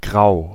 Ääntäminen
Ääntäminen US : IPA : [ɡreɪ] UK Tuntematon aksentti: IPA : /ɡɹeɪ/